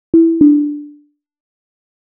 Autopilot Disengage.wav